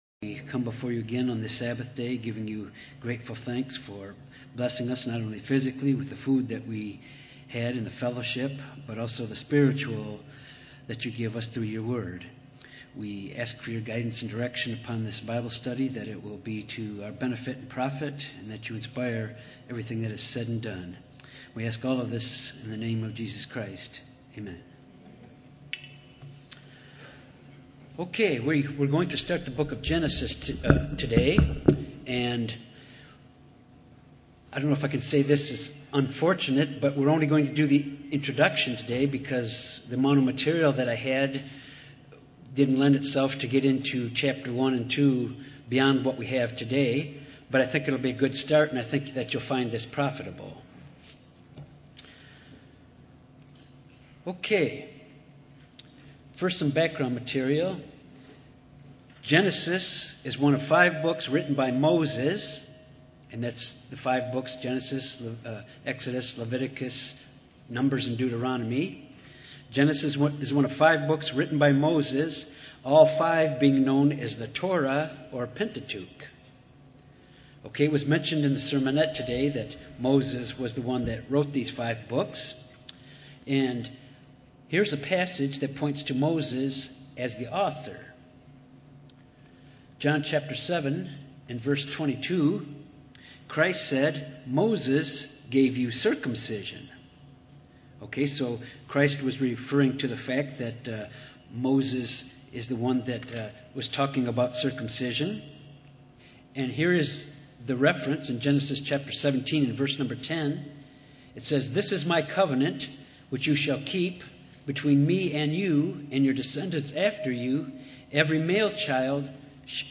This bible study deals with the background to the book of Genesis.
Given in Little Rock, AR